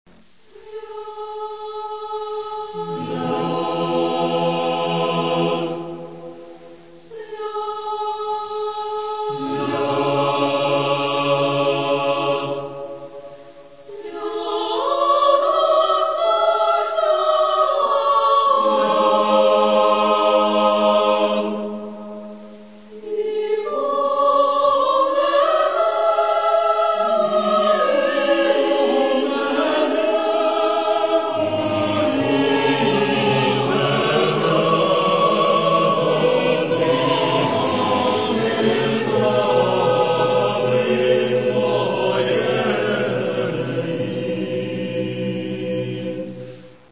Літургійні твори